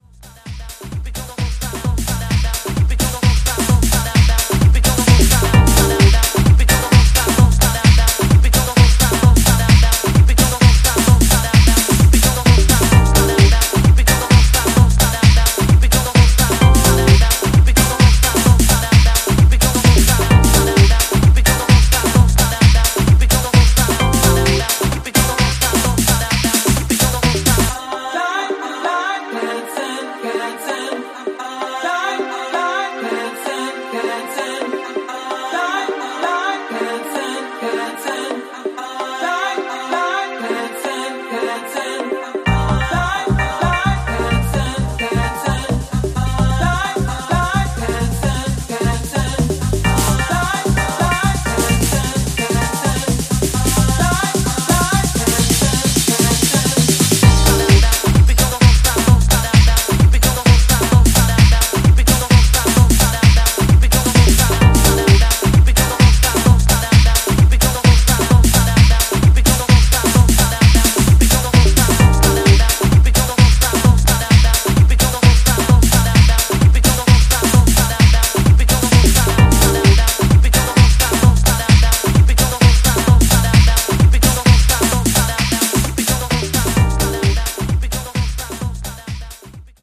多幸感溢れるブレイクを据えた